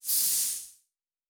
pgs/Assets/Audio/Sci-Fi Sounds/MISC/Air Hiss 1_01.wav at master
Air Hiss 1_01.wav